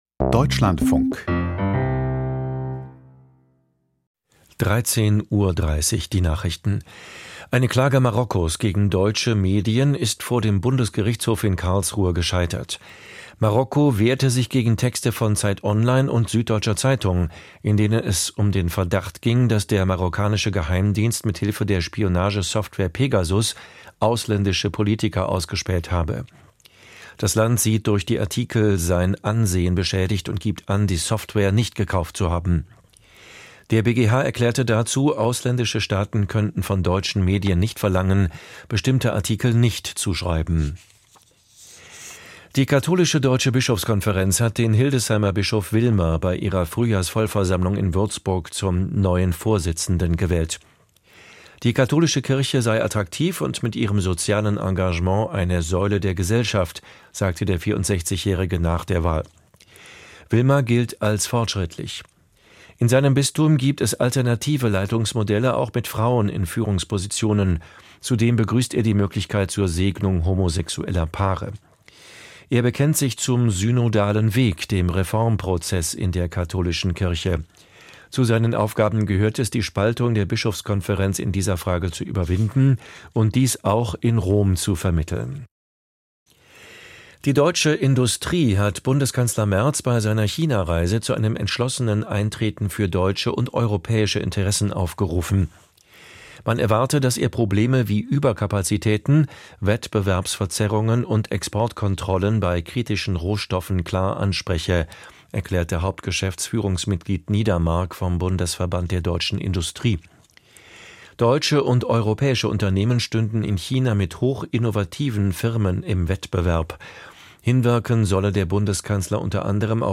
Die Nachrichten vom 24.02.2026, 13:30 Uhr